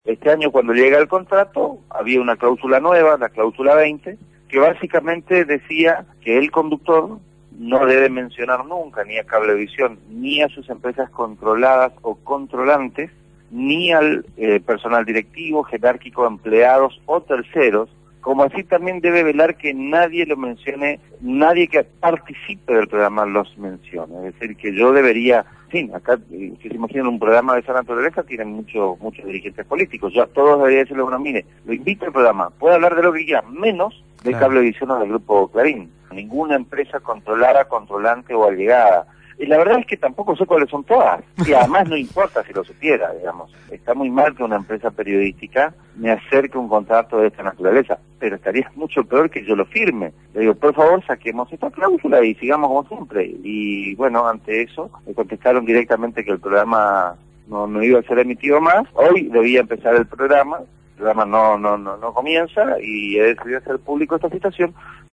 entrevistado por el programa «Punto de Partida» (Lunes a viernes de 7 a 9 de la mañana) de Radio Gráfica relató como la empresa Cablevisión levanta su programa de la grilla.